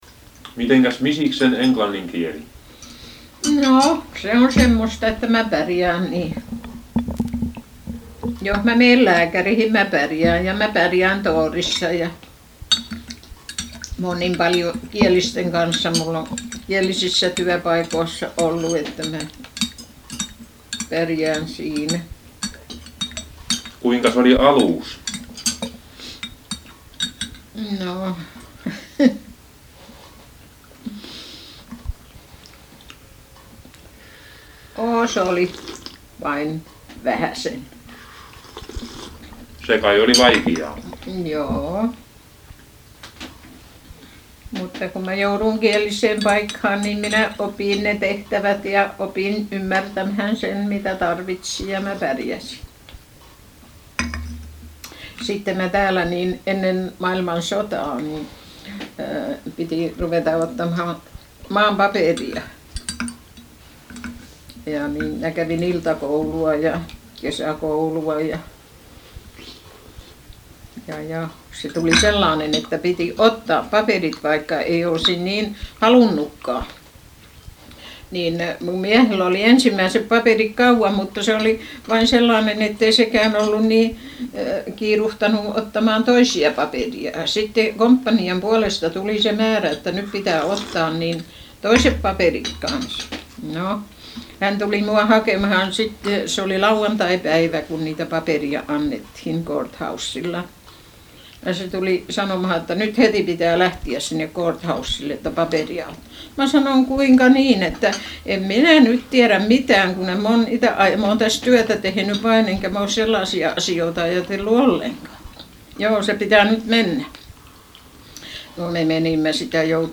Heinäkuun 11. päivä vuonna 1965. Virginian kaupunki Minnesotan osavaltiossa Yhdysvalloissa.
Kahvitellaan, nauhuri nauhoittaa.
Haastattelu on nauhoitettu 11.7.1965, Virginiassa, Minnesotan osavaltiossa.